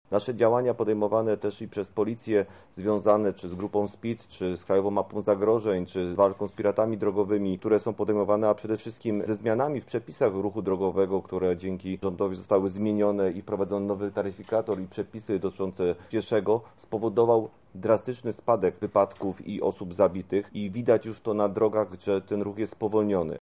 O działaniach policji w tym zakresie mówi Artur Bielecki, Komendant Wojewódzki Policji w Lublinie: